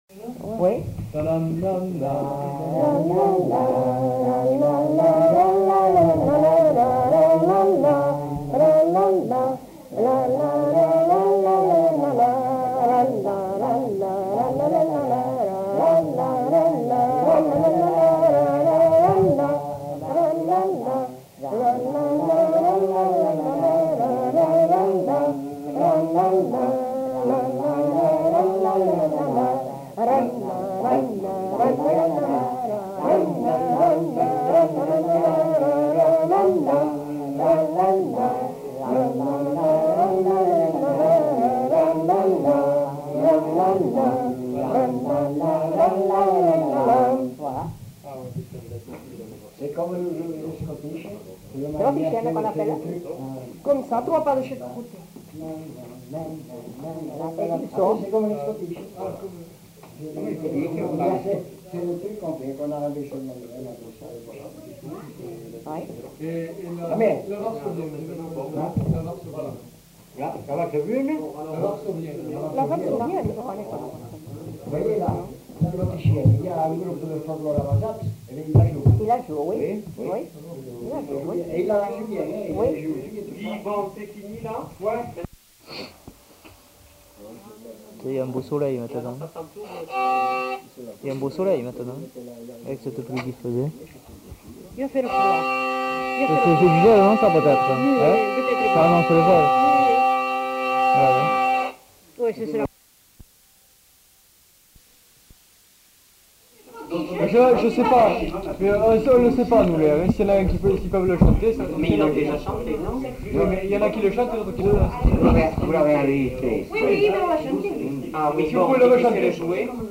Aire culturelle : Grandes-Landes
Lieu : Luxey
Genre : chant
Type de voix : voix mixtes
Production du son : fredonné
Danse : scottish